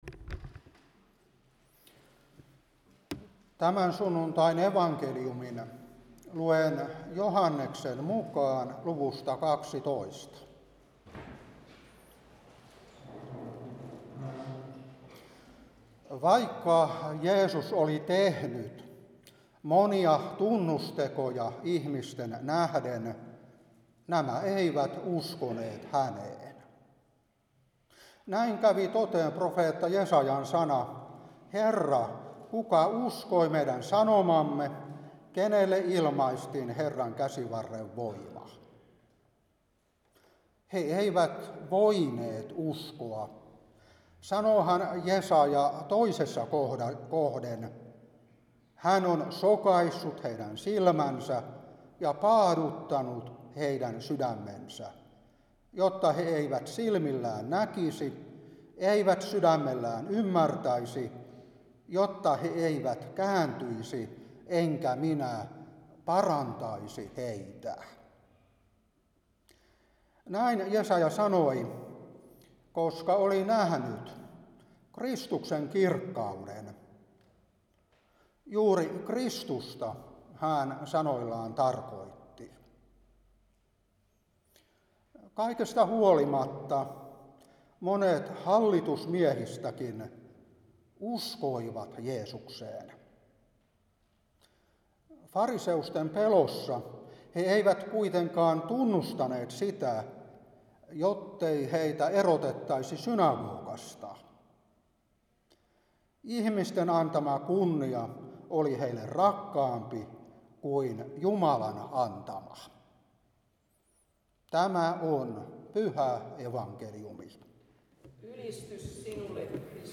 Saarna 2026-3 Joh.12:37-43. 1.Joh.1:1,5-7.